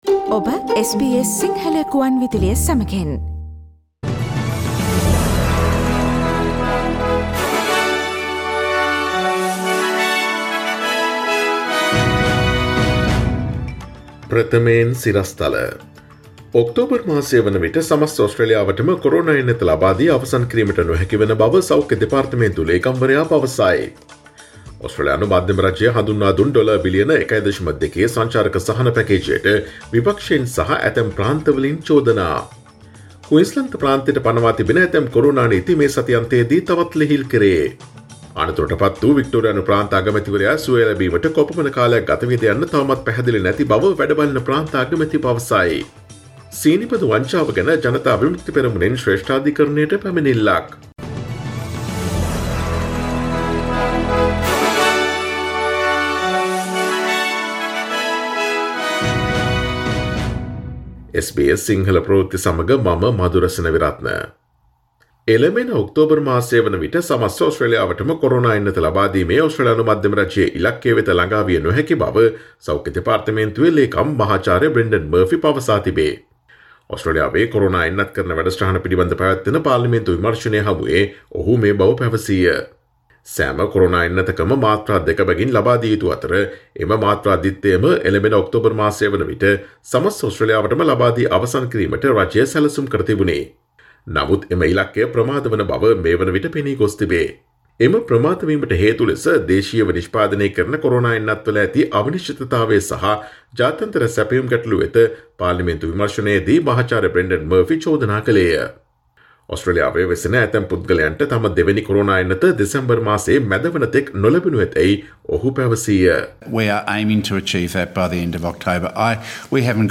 Here are the most prominent Australian and Sri Lankan news highlights from SBS Sinhala radio daily news bulletin on Friday 12 March 2021.